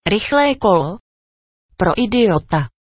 Play, download and share fast bike original sound button!!!!
fast-bike.mp3